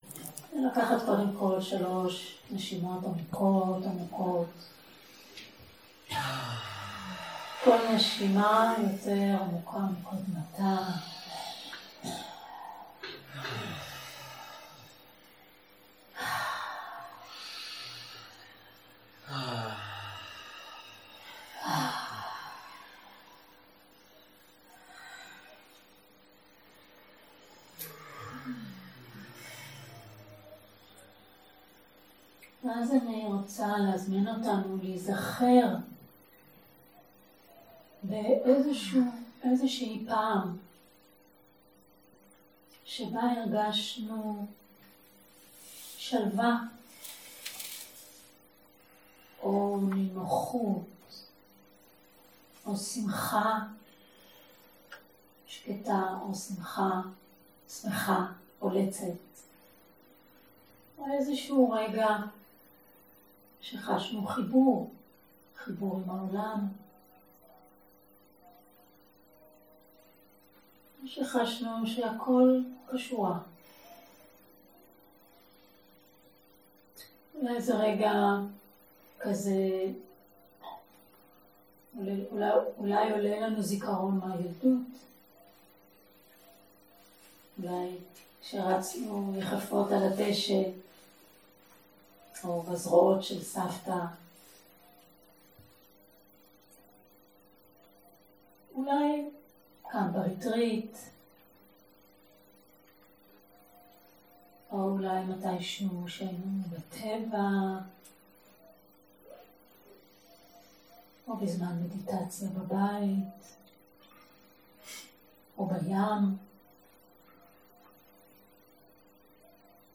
שיחות דהרמה